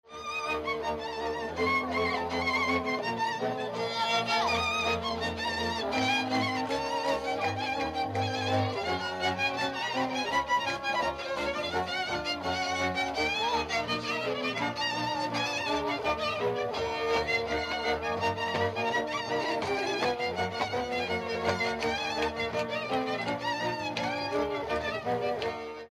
Dallampélda: Hangszeres felvétel
Erdély - Kolozs vm. - Kolozs
hegedű
kontra
bőgő
Műfaj: Csárdás
Stílus: 4. Sirató stílusú dallamok